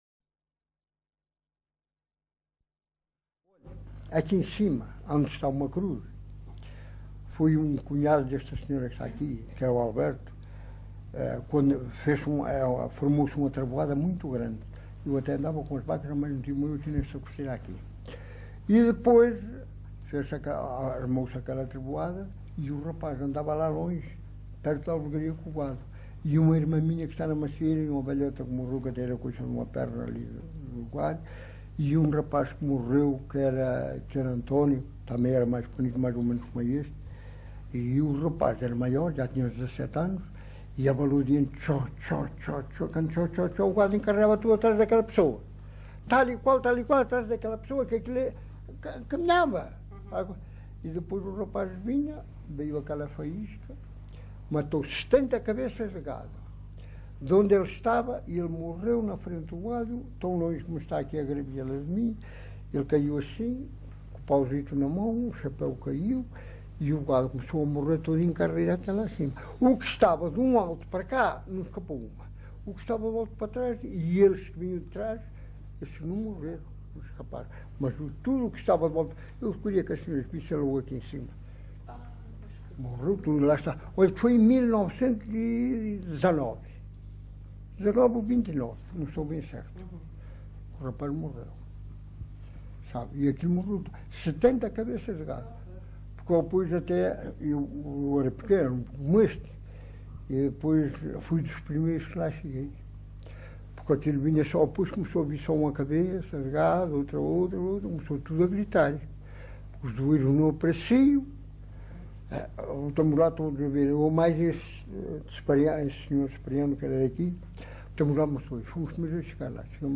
LocalidadeCovo (Vale de Cambra, Aveiro)